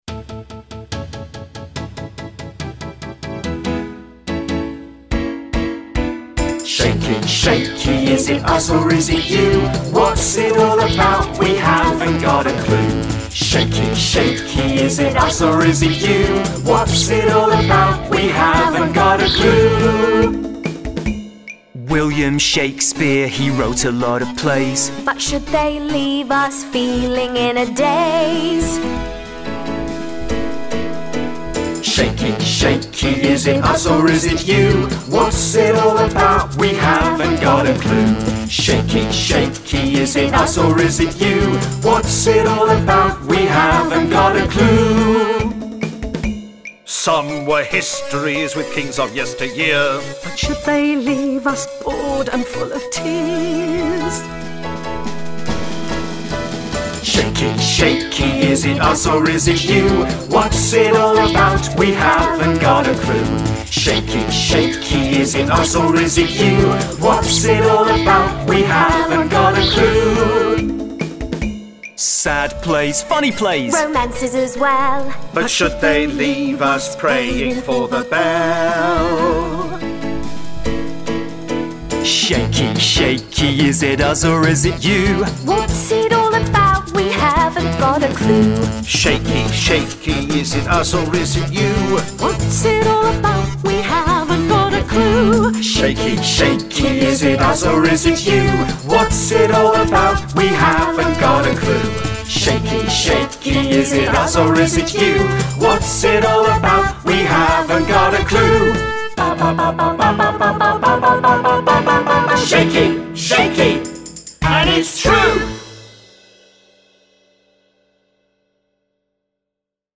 Download full vocal